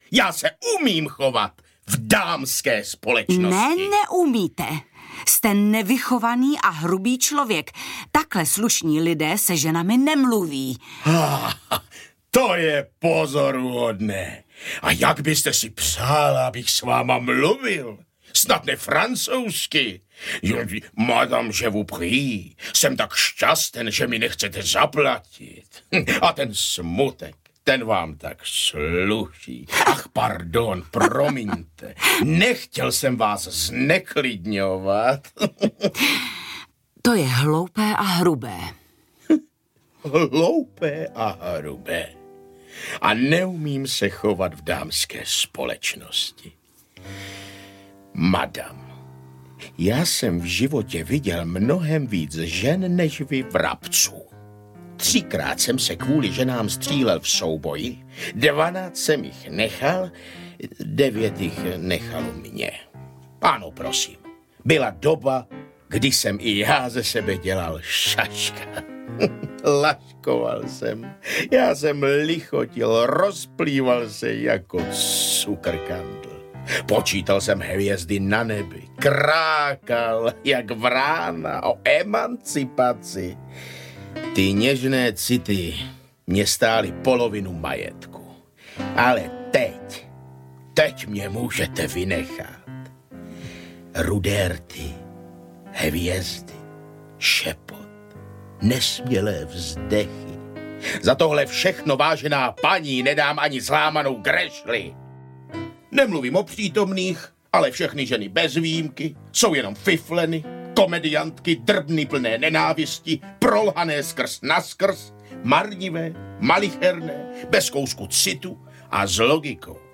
Medvěd audiokniha
Ukázka z knihy